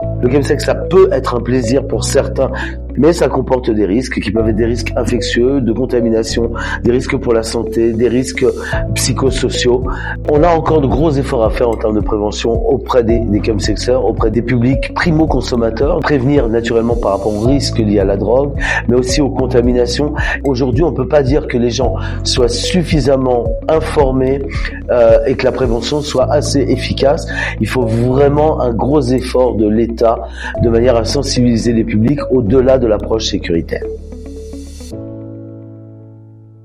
3.chemsex-les-risques-et-preventions-musique.mp3